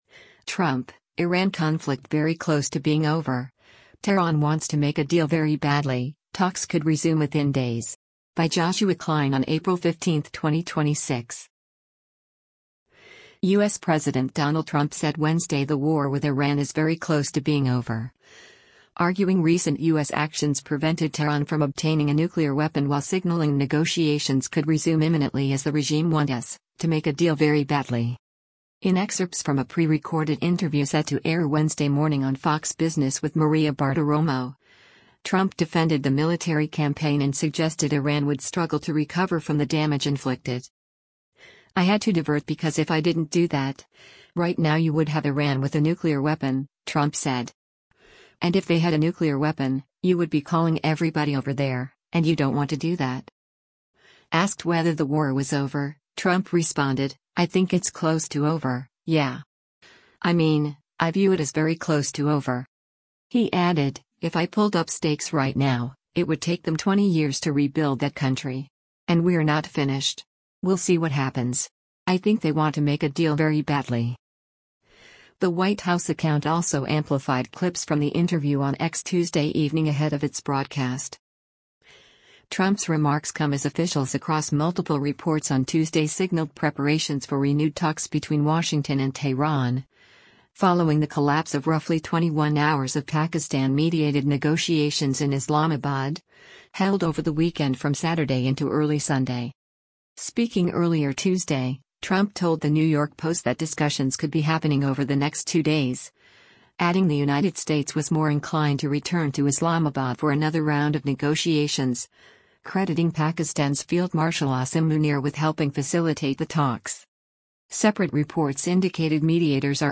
US President Donald Trump speaks to members of the media outside the Oval Office of the Wh